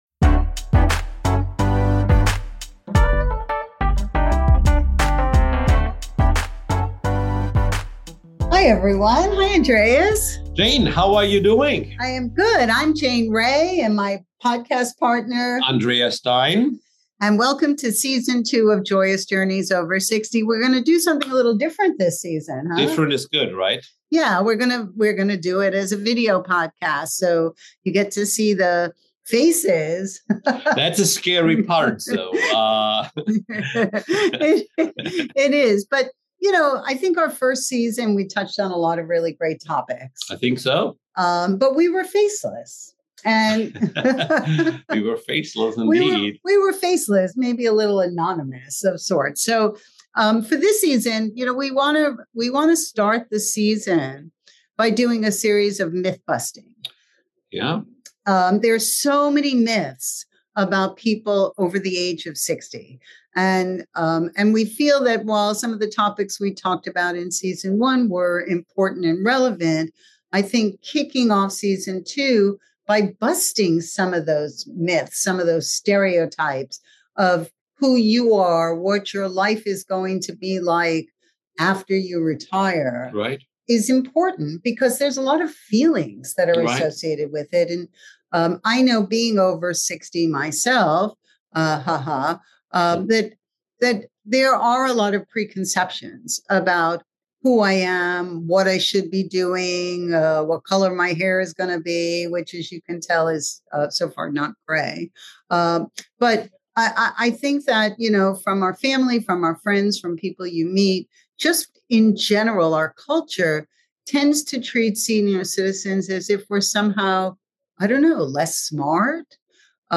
have a frank discussion about the hidden fears we face as we enter the fourth quarter of life. We share some ideas for using that fear to ensure that the focus of this chapter is in making as many wonderful experiences and memories as possible.